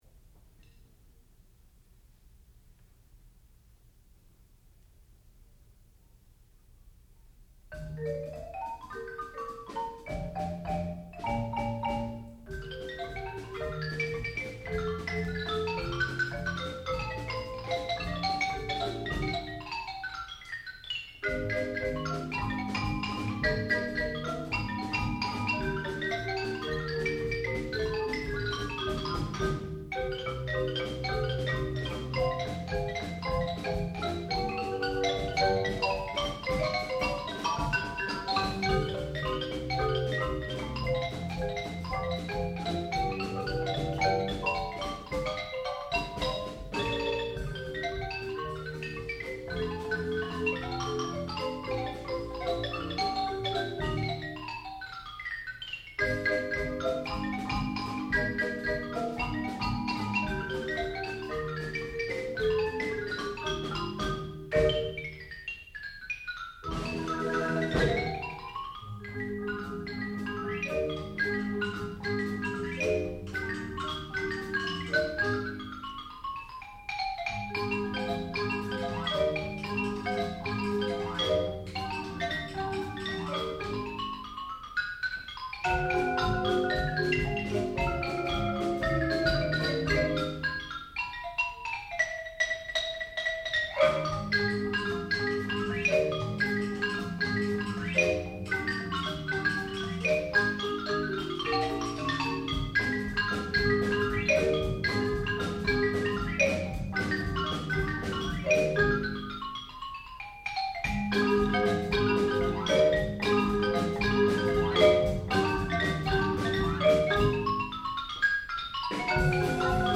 sound recording-musical
classical music
xylophone and Shepherd School Percussion Ensemble